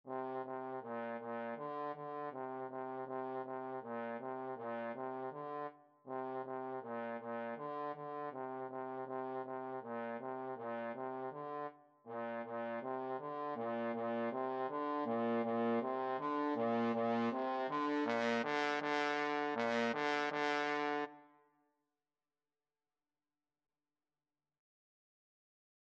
2/4 (View more 2/4 Music)
Bb3-D4
Trombone  (View more Beginners Trombone Music)
Classical (View more Classical Trombone Music)